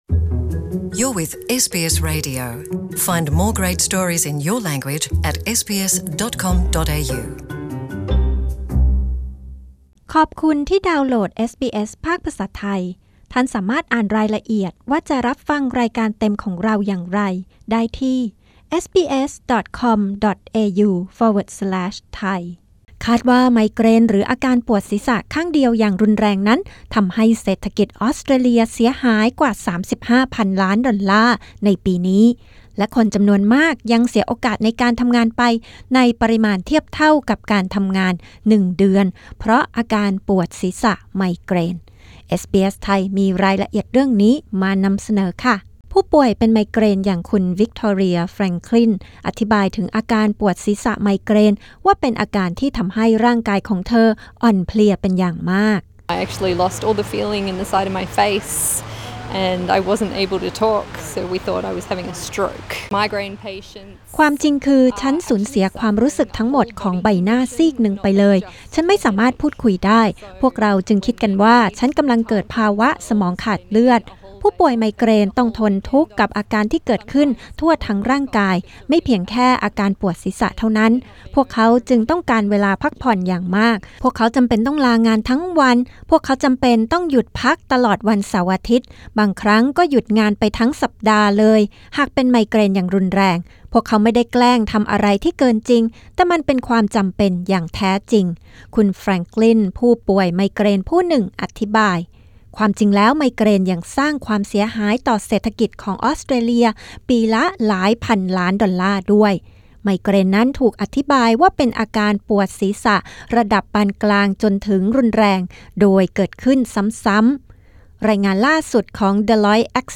กด (►) ที่ภาพด้านบน เพื่อฟังเสียงรายงานข่าวเรื่องนี้เป็นภาษาไทย